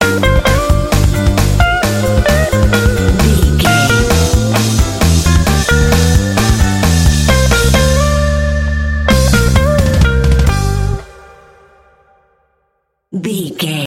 This bluesy track is full of urban energy.
Aeolian/Minor
funky
groovy
energetic
driving
electric guitar
electric organ
bass guitar
drums
blues
jazz